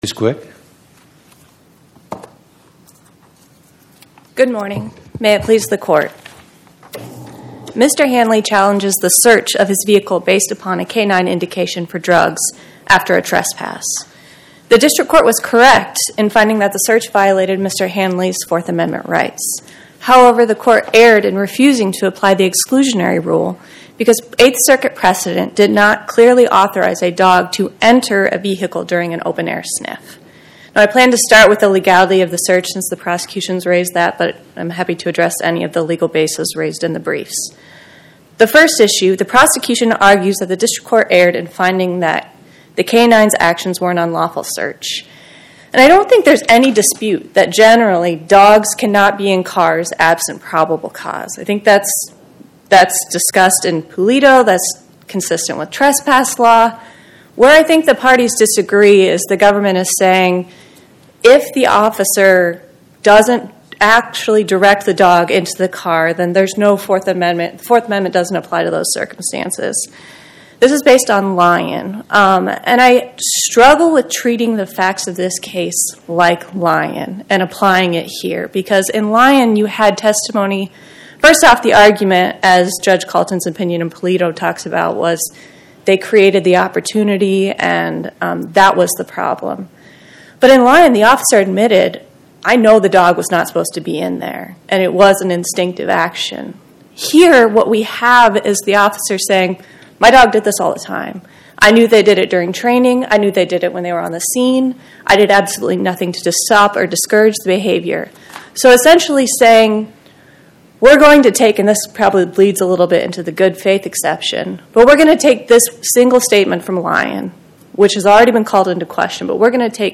Oral argument argued before the Eighth Circuit U.S. Court of Appeals on or about 09/16/2025